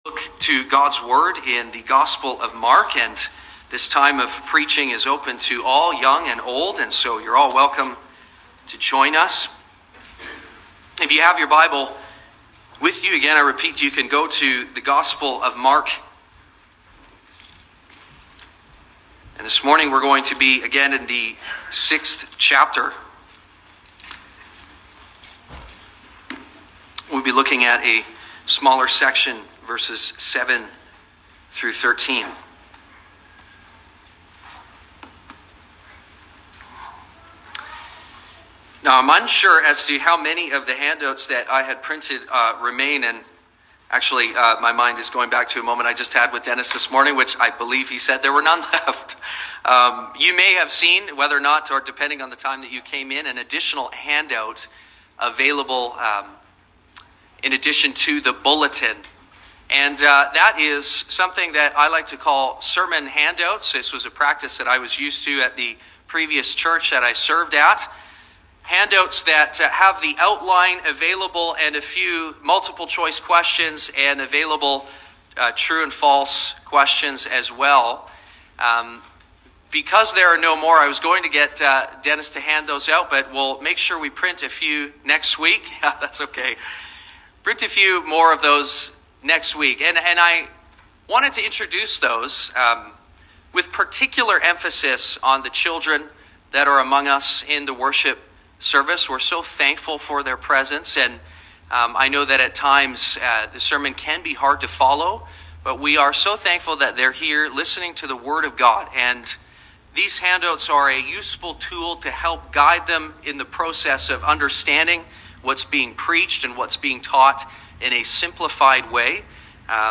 Sermons | Sonrise Community Baptist